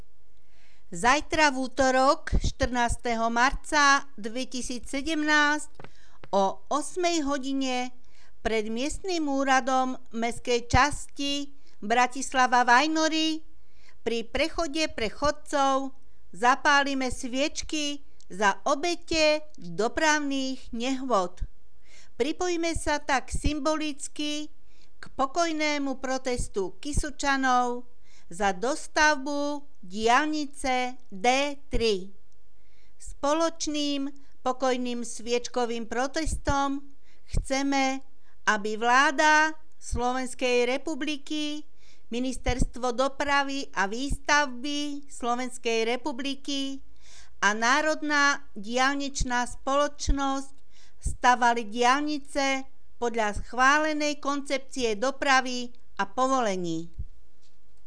Hlásenia miestneho rozhlasu 13.3.2017 (Sviečka za obete dopravných nehôd)